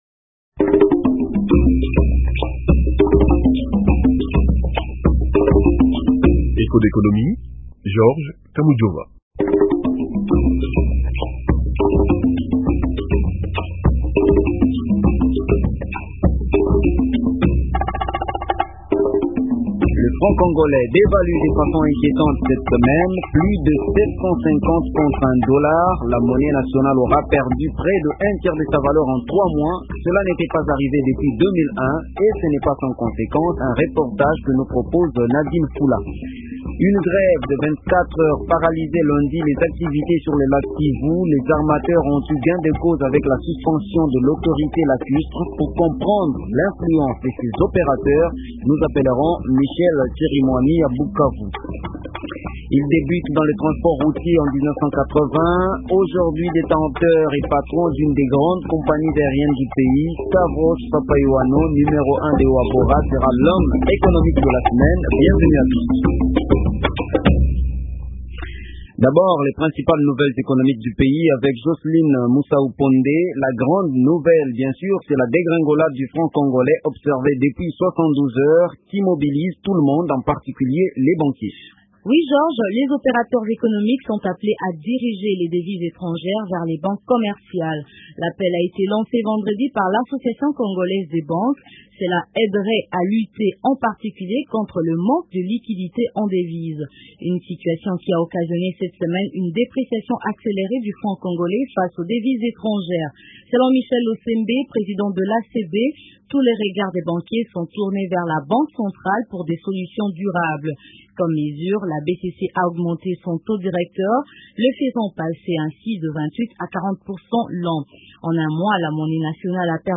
Reportage au grand marché de Kinshasa.
Depuis la semaine dernière, le Franc congolais est dans une situation instable par rapport aux devises étrangères. C’est le gros de l’émission Échos d’économie avec un reportage au grand marché de Kinshasa. Le magazine économique de Radio Okapi vous amène scruter également l’influence des armateurs a Bukavu.